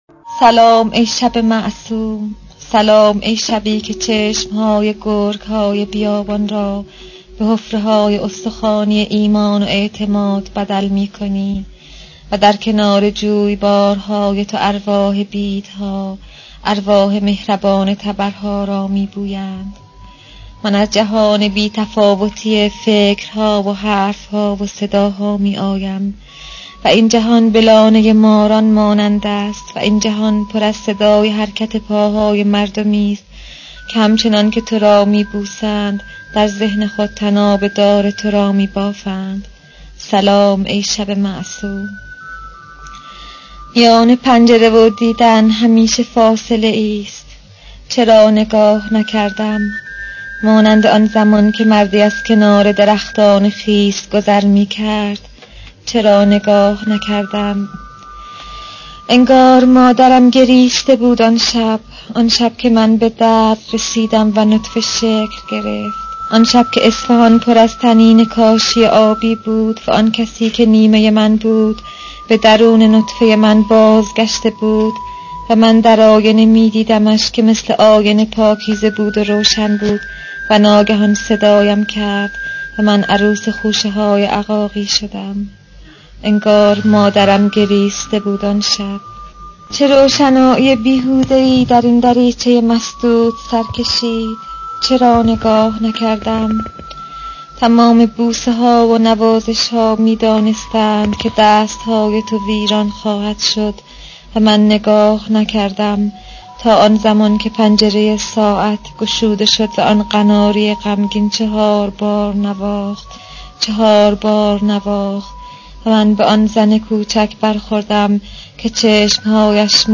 موزیک دهه شصتی